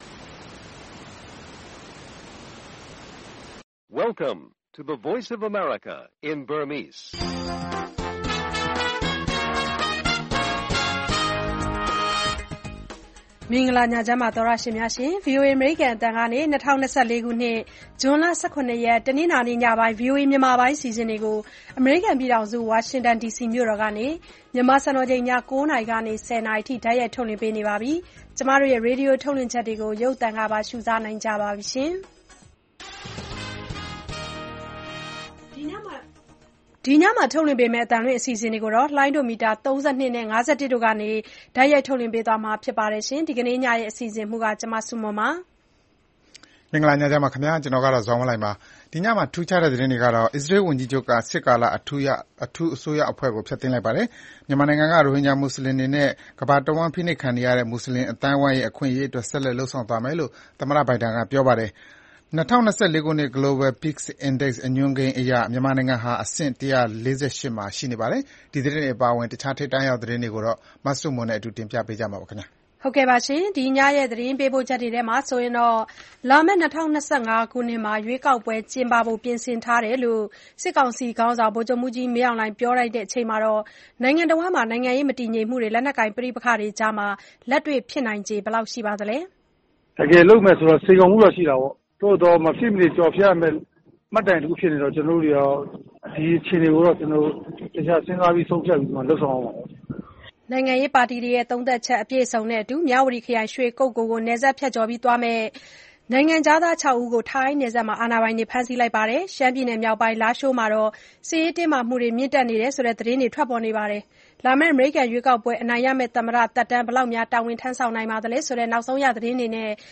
ရိုဟင်ဂျာတွေ အခွင့်အရေး အမေရိကန် ဆက်လက်အားပေးသွားဖို့ သမ္မတဘိုင်ဒင် ကတိပြု၊ စစ်ကောင်စီရဲ့ ရွေးကောက်ပွဲ အစီအစဉ်အပေါ် နိုင်ငံရေးပါတီတချို့အမြင်၊ စီးပွားရေးပညာရှင် ပါမောက္ခ Sean Turnell နဲ့ မေးမြန်းခန်း စတာတွေအပြင် စီးပွားရေး၊ လူမှုရှုခင်း သီတင်းပတ်စဉ် အစီအစဉ်တွေကို တင်ဆက်ထားပါတယ်။